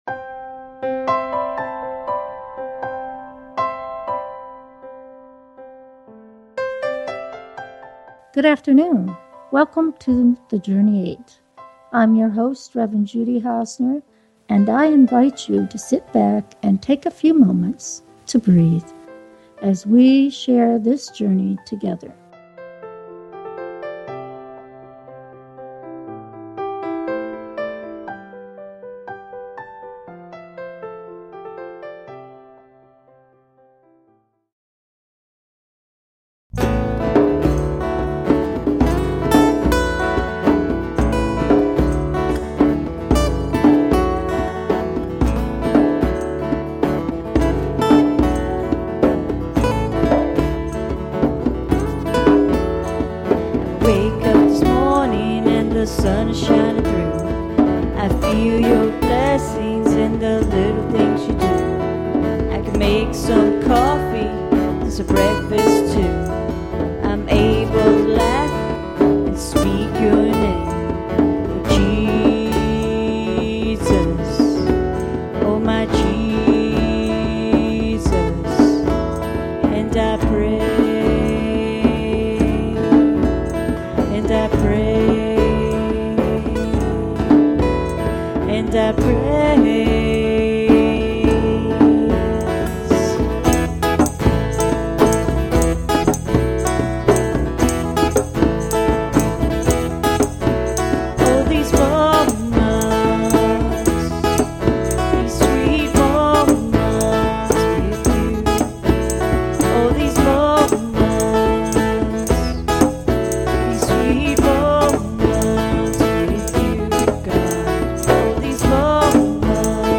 The Journey 8 is a Christian based talk show that crosses the barriers of fear and hatred to find understanding and tolerance for all of God’s children.